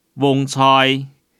Hakka tts 用中文字典方式去mapping客語語音 客語語音來源 1.